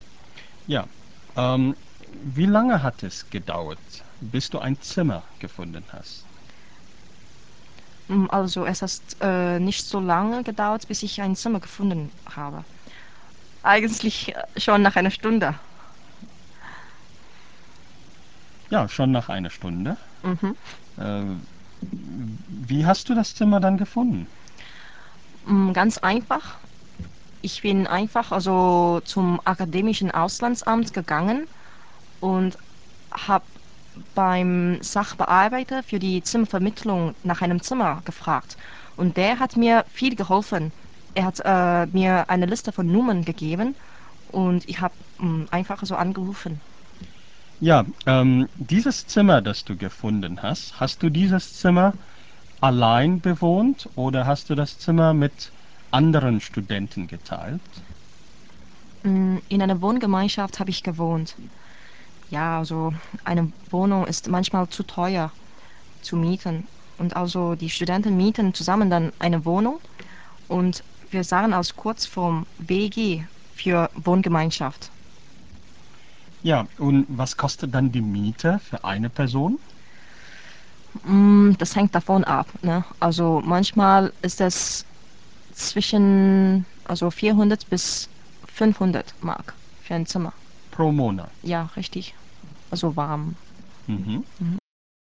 Eine singapurische Studentin über ihre Erfahrungen in Deutschland - Teil I
Hörverständnis